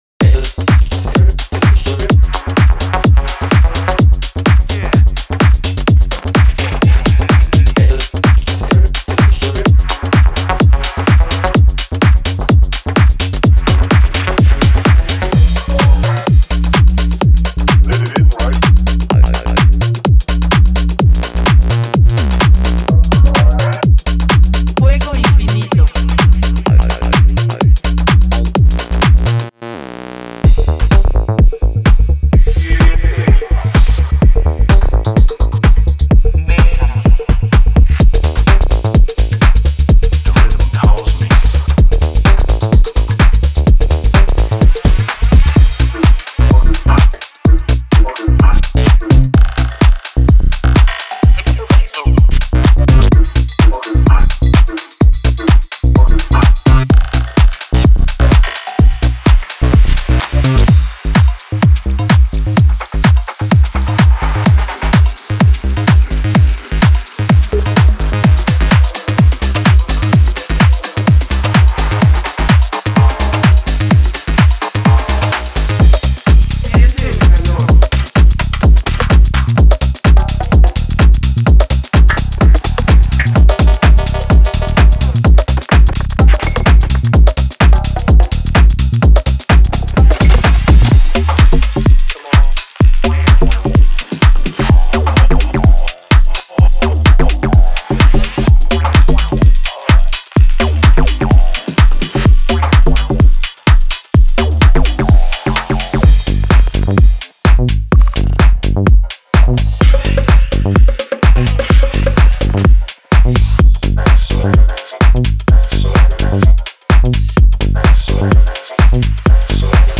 ベースループ → 深く低音を揺らすグルーヴ、催眠的な動きと力強いパンチを備えたライン。
シンセループ → 洗練されたスタブ、催眠的なアルペジオ、ダンスフロアのエネルギーと抑制のバランスを意識したコード。
ハットループ → スウィング感、空気感、煌めきを加えるクリスプでダイナミックなハイハットリズム。
パーカッションループ → スキッタリング、トライバル風味、グリッチを取り入れたパーカッシブ要素。
ボーカルループ → 抽象的なフレーズ、催眠的なスポークンパーツ、大気的なチョップ。
テクスチャーループ → 深いサウンドスケープ、進化するアンビエンス、グラニュラーなレイヤー。
グリッチループ → ねじれた編集、マイクロカット、スタッターグルーヴで予測不能なスリルを演出。
FX＆ワンショット → ライザー、ダウンシフター、インパクト、精密なワンショットドラムをフル装備。
デモサウンドはコチラ↓
Genre:Tech House